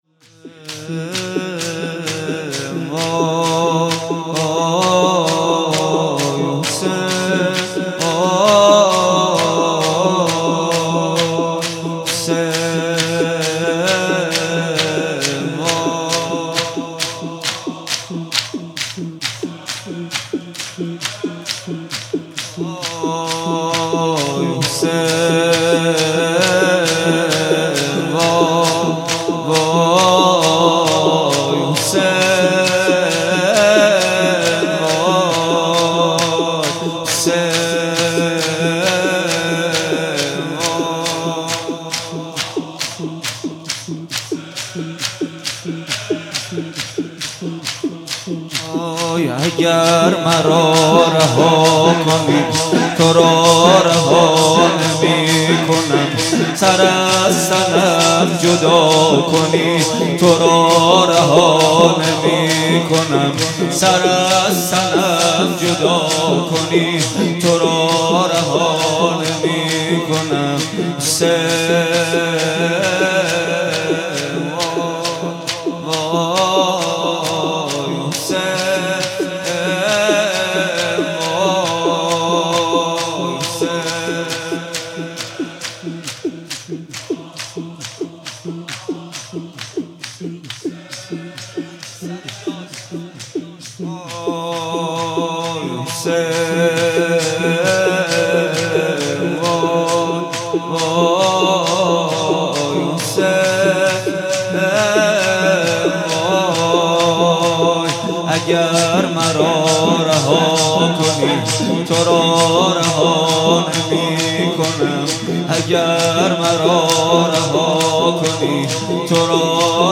بيست و هفتم صفر 95 - شور - نغمه خوانی